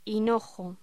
Locución: Hinojo